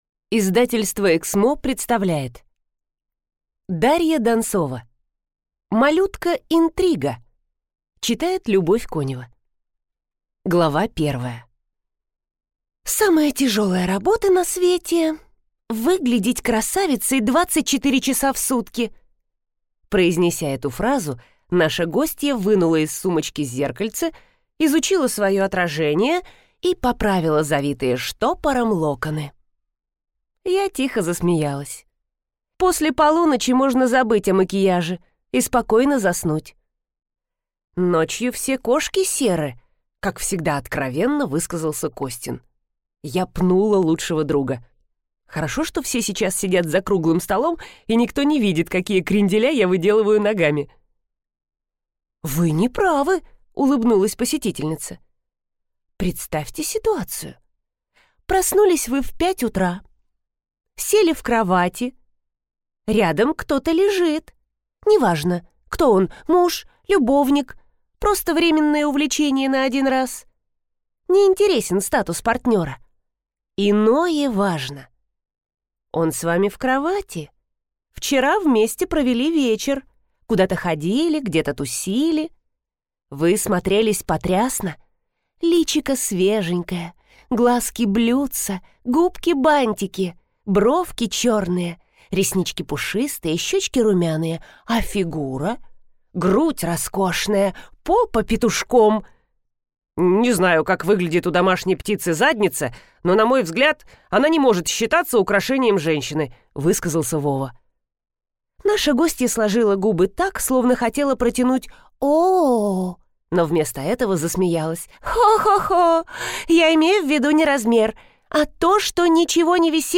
Аудиокнига Малютка Интрига | Библиотека аудиокниг